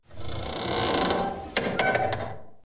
TCDOOR4.mp3